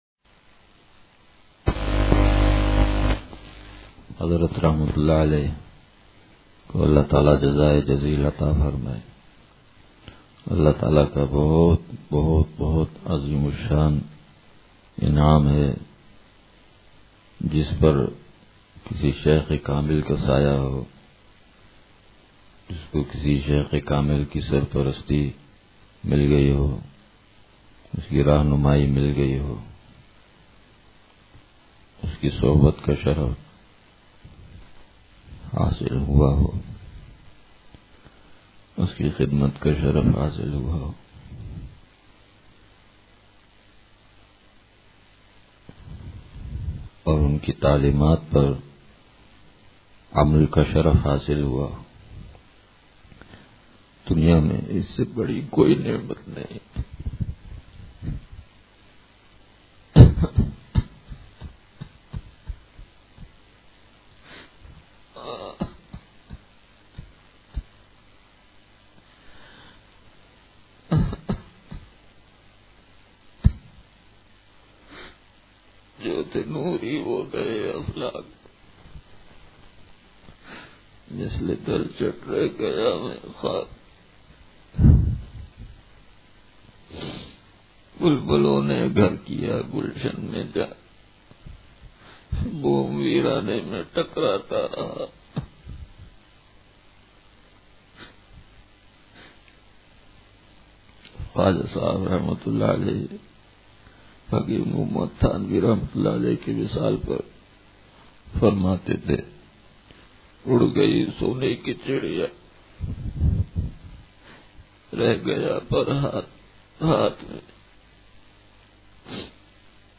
بیان – اتوار